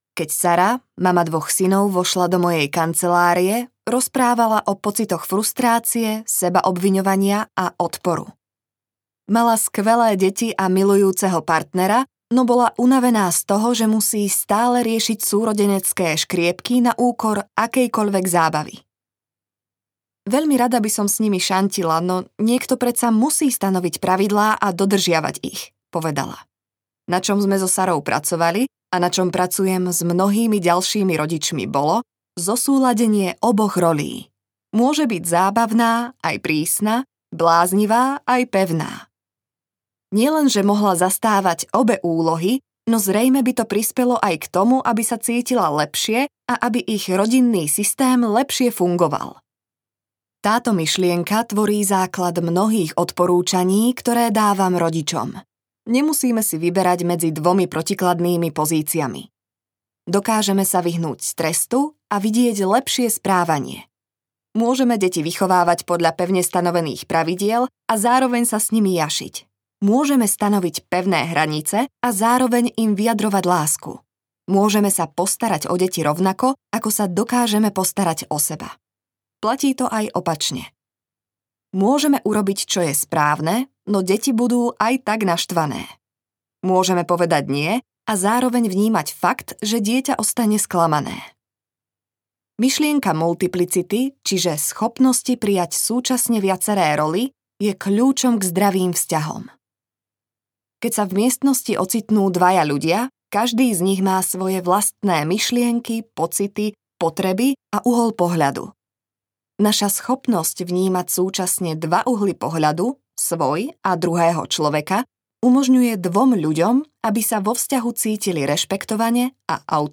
Audiokniha Objavte v sebe dobrého rodiča - Becky Kennedy | ProgresGuru